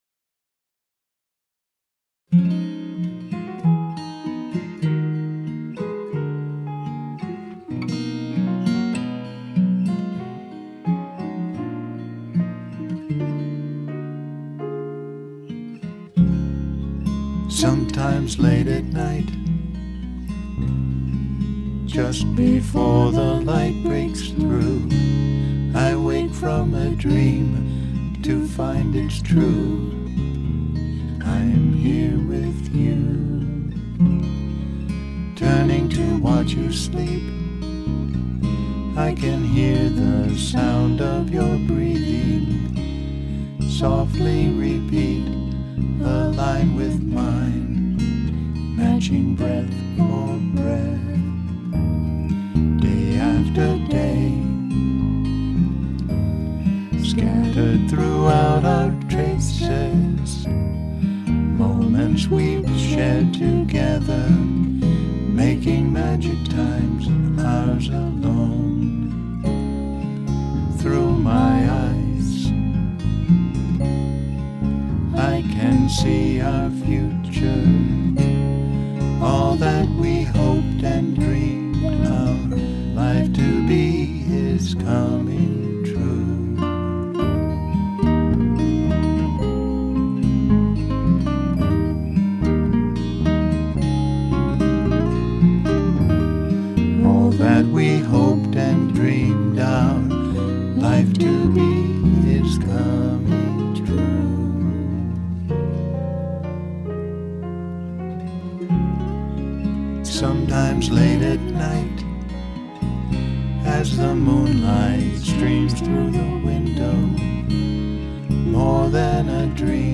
Vocals and Production
Guitar